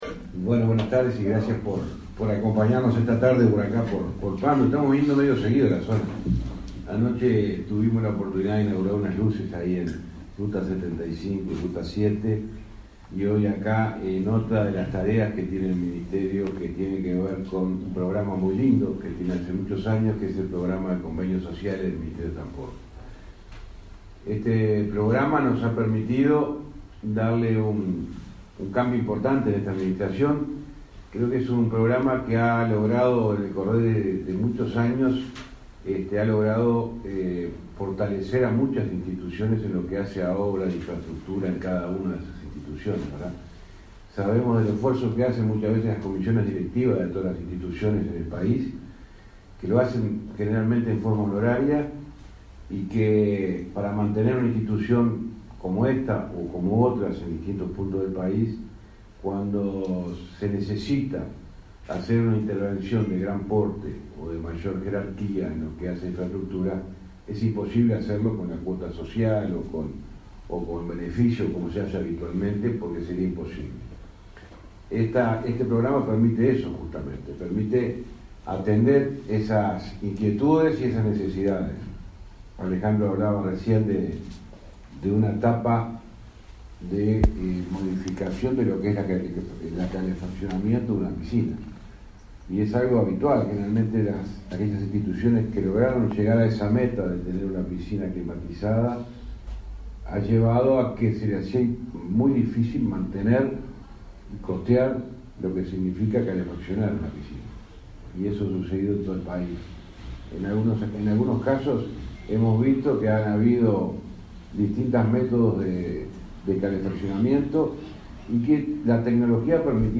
Palabras del ministro de Transporte, José Luis Falero
Palabras del ministro de Transporte, José Luis Falero 28/07/2023 Compartir Facebook X Copiar enlace WhatsApp LinkedIn El Ministerio de Transporte y Obras Públicas (MTOP) firmó, este 28 de julio, un convenio social con el Centro de Protección de Choferes de la localidad de Pando, en el departamento de Canelones. Disertó en el evento el titular de la cartera, José Luis Falero.